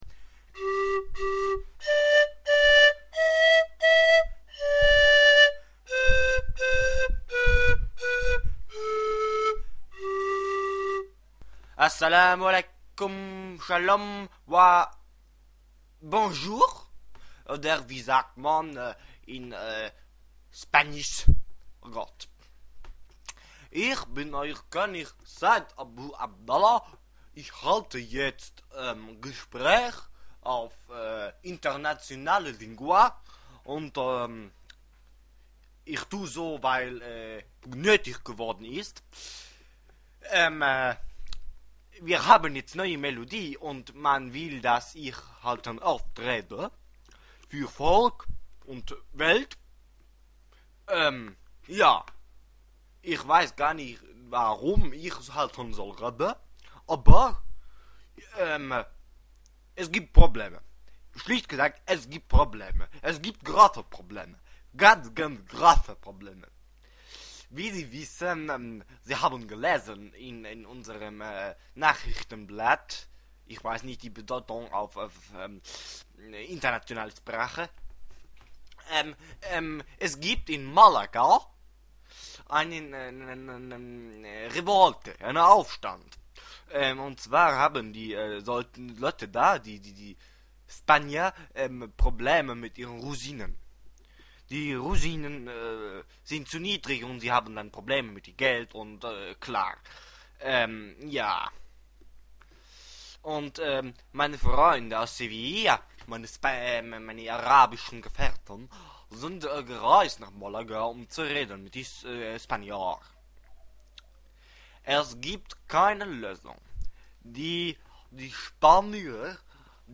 Nachdem die arabischen Gelehrten die spanische Menschenmasse nicht die spanische Menschnmasse beruhigen konnte, ergriff der König am Vormittag das Wort in diesem Nachrichtendienst. Man solle Rosinen doch als Sultaninen verkaufen und so als völlig neues Produkt.
Rosinenaufstandrede.mp3